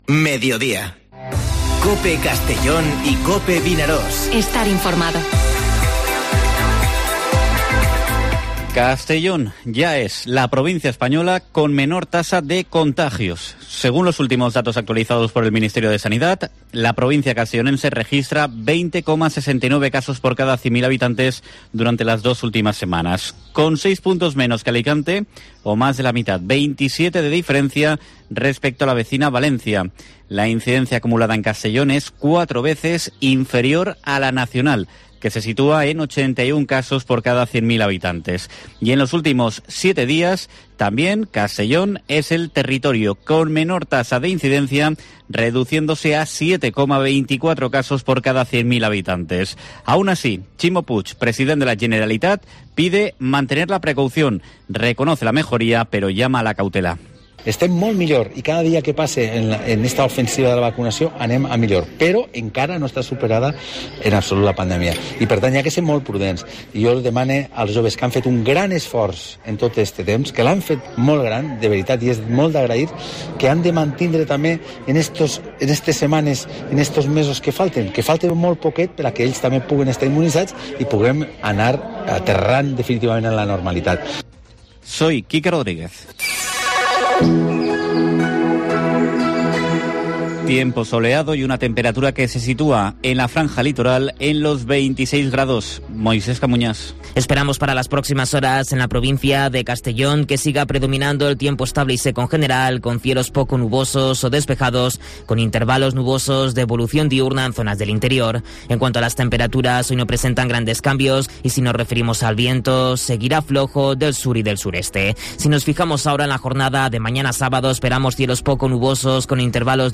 Informativo Mediodía COPE en la provincia de Castellón (25/06/2021)